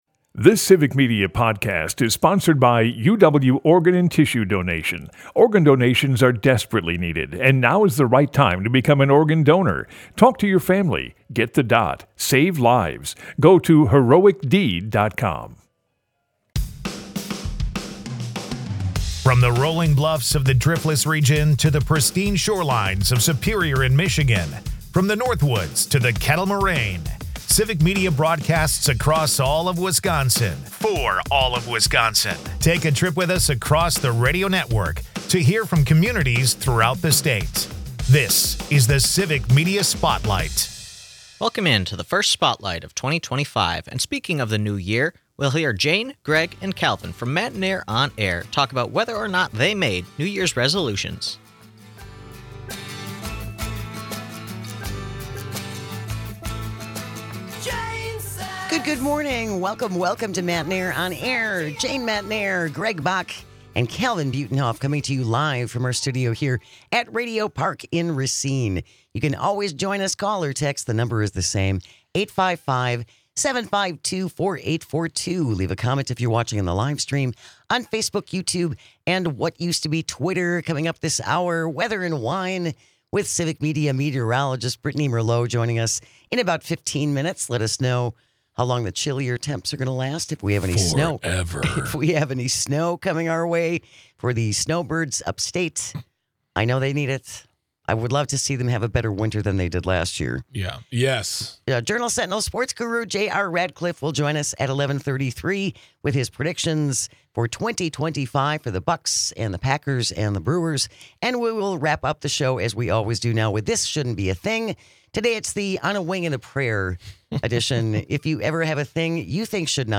We take calls and read texts from the listeners on this and give advice on how to reach goals for yourself.